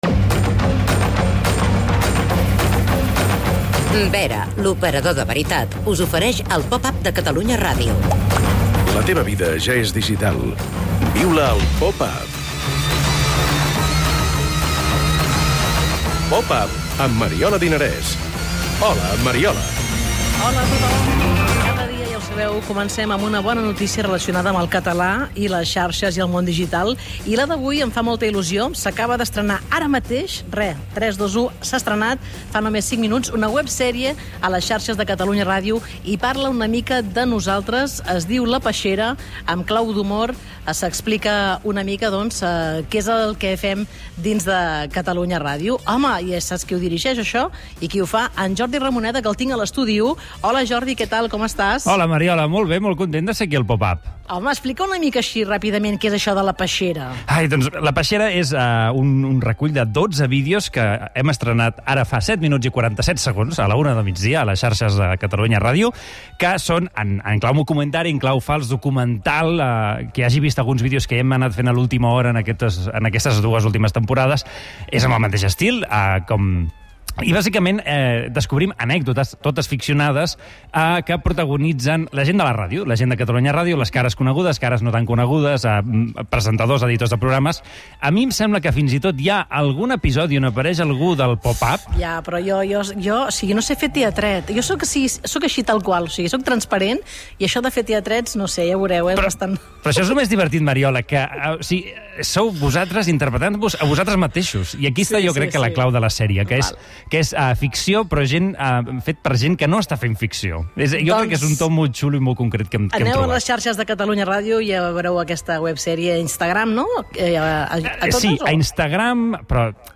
Fem el "Popap" des del Sónar, que celebra el seu 30è aniversari.